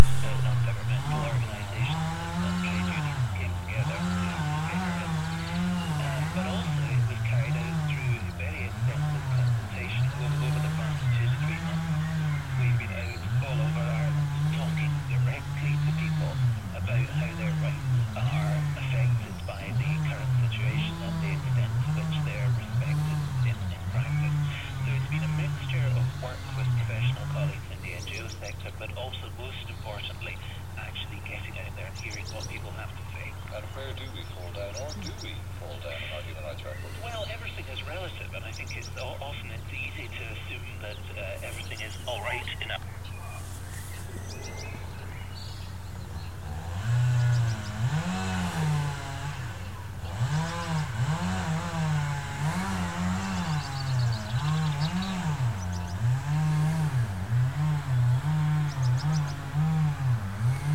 just after firing up the barbeque, neighbours start using power tools